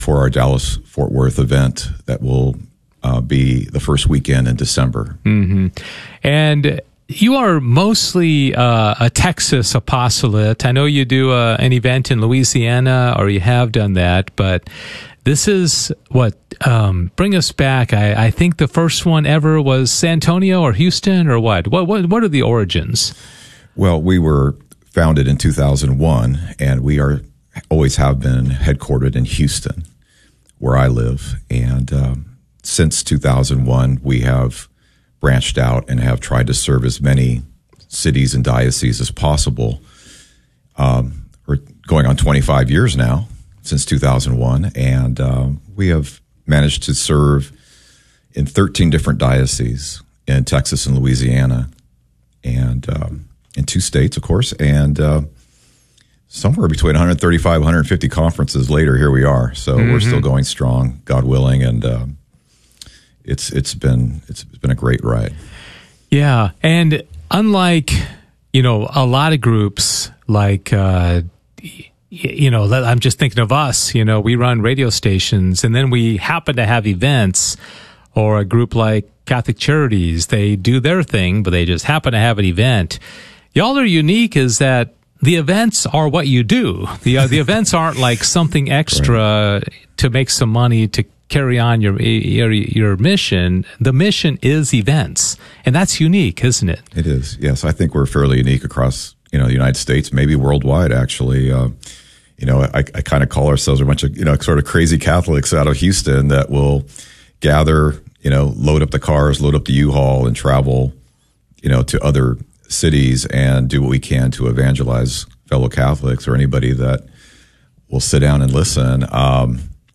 KATH Interview of the Week - Saturday June 28, 2025